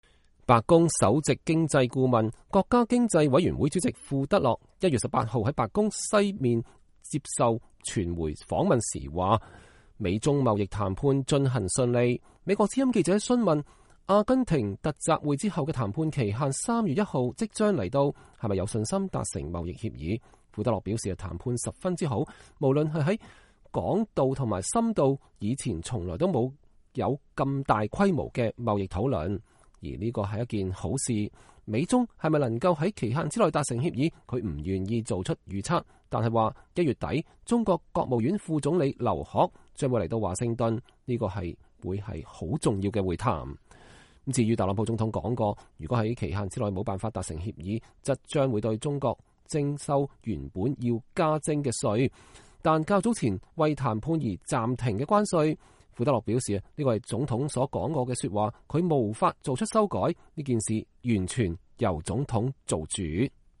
白宮首席經濟顧問，國家經濟委員會主席庫德洛1月18號在白宮外接受傳媒訪問。